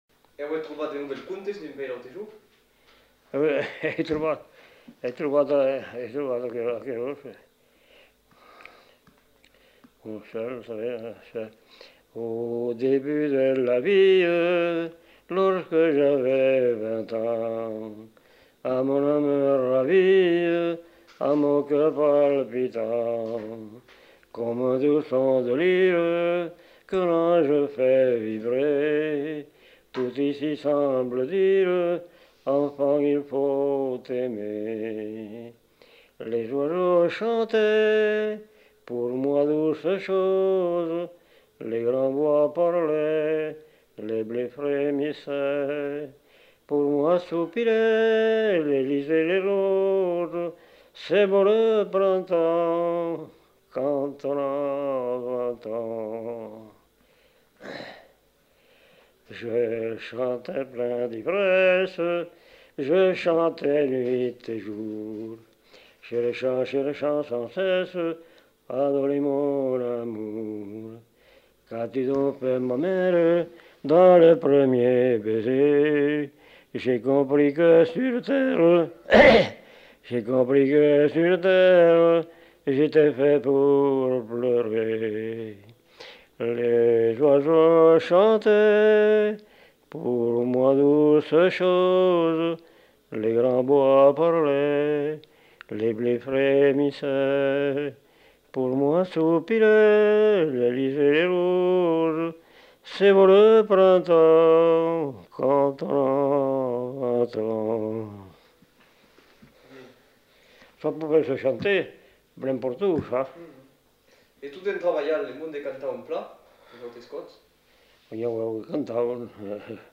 Aire culturelle : Lomagne
Lieu : Faudoas
Genre : chant
Effectif : 1
Type de voix : voix d'homme
Production du son : chanté